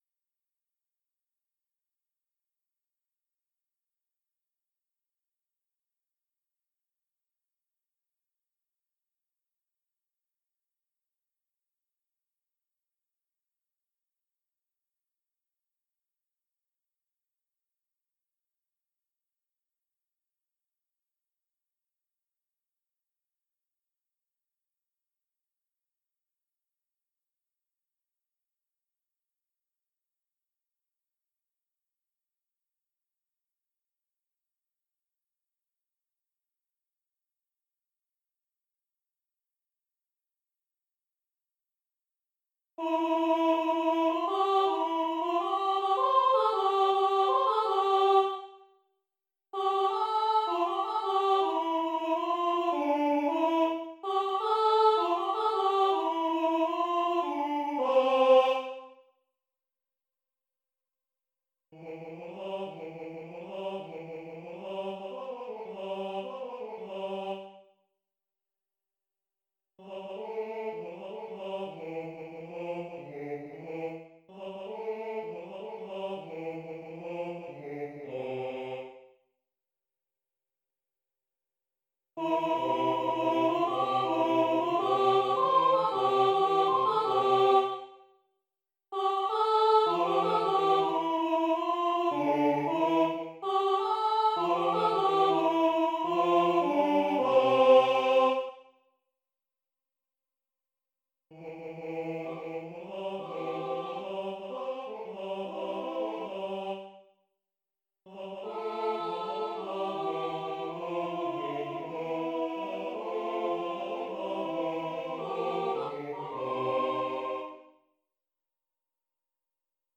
L’àngel i els pastors – cor